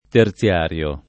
terziario [ ter ZL# r L o ]